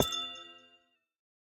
Minecraft Version Minecraft Version snapshot Latest Release | Latest Snapshot snapshot / assets / minecraft / sounds / block / amethyst_cluster / place2.ogg Compare With Compare With Latest Release | Latest Snapshot